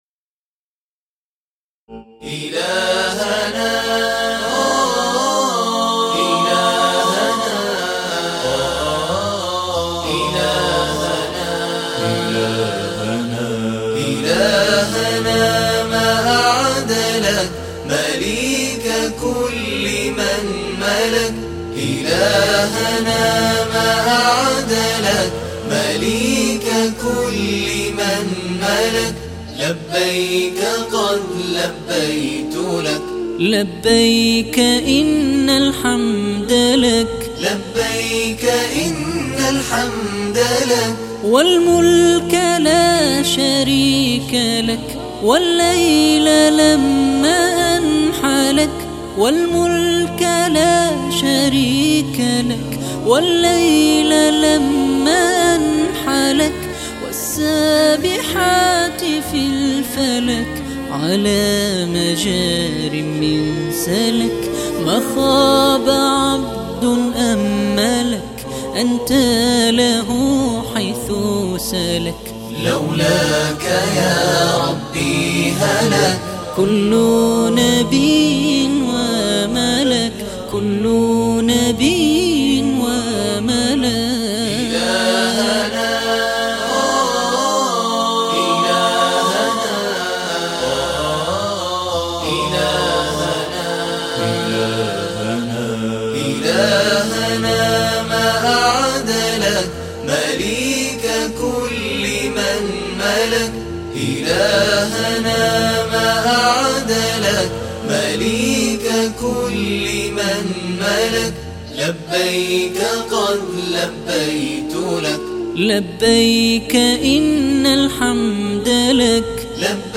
مـن فضلك اخــي جــيدا الأنــــشوده الــتي فـي تـــوقيـعــكم